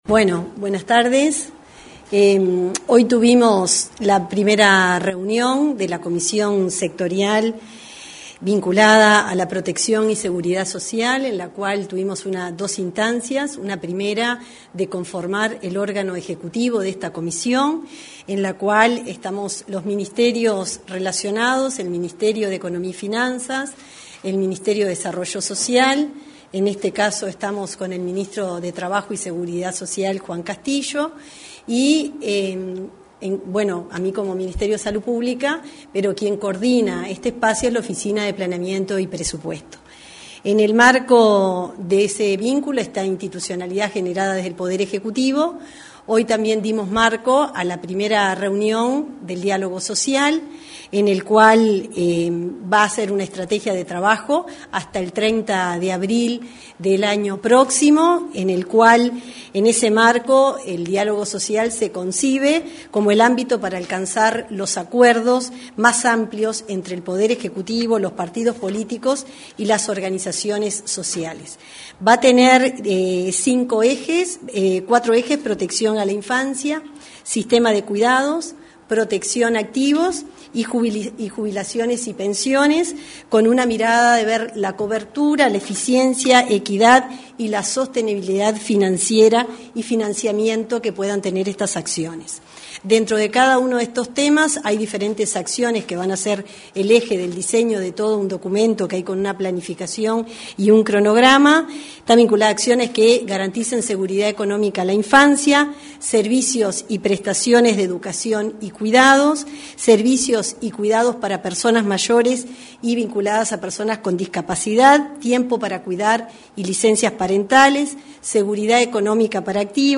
Conferencia de prensa: Primera sesión del Comité Ejecutivo de la Comisión Sectorial de Protección y Seguridad Social 22/04/2025 Compartir Facebook X Copiar enlace WhatsApp LinkedIn Este martes 22, luego de la primera sesión del Comité Ejecutivo de la Comisión Sectorial de Protección y Seguridad Social, se realizó una conferencia de prensa en Torre Ejecutiva. En la oportunidad, se expresaron la ministra de Salud Pública, Cristina Lustemberg, y el ministro de Trabajo y Seguridad Social, Juan Castillo.